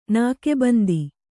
♪ nāke bandi